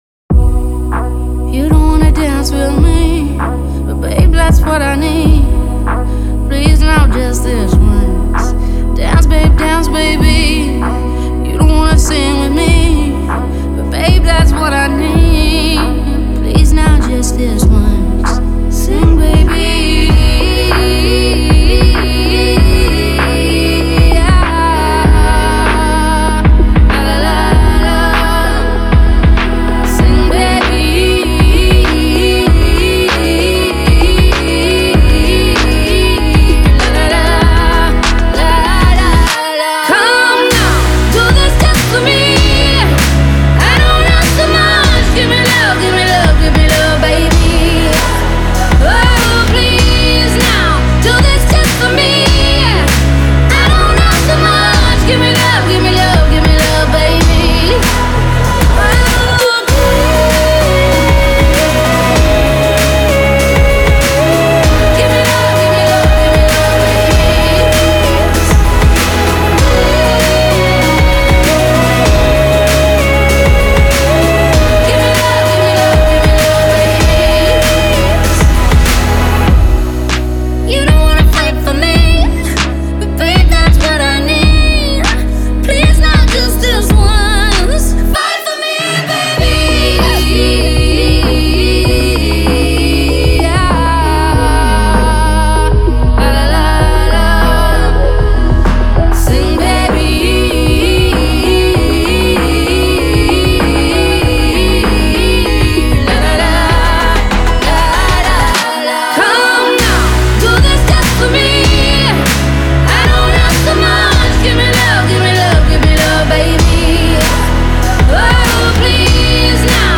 I love the voice and it makes me dance